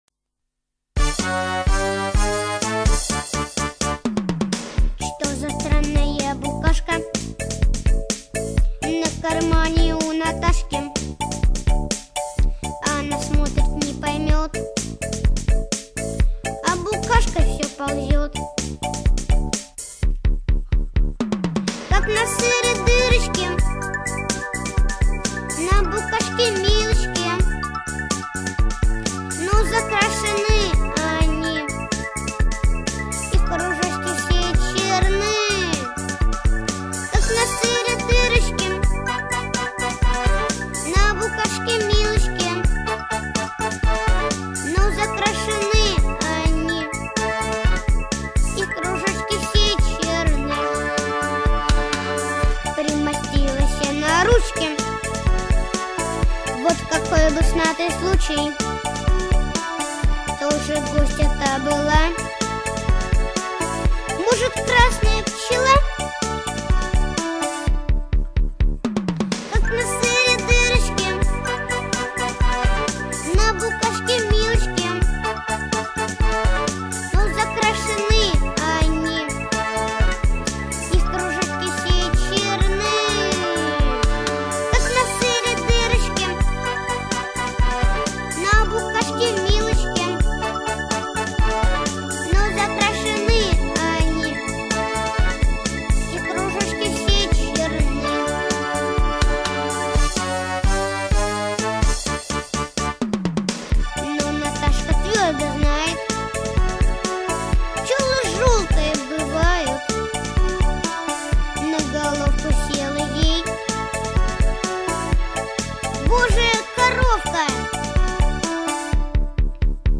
• Жанр: Детские песни
Детская песня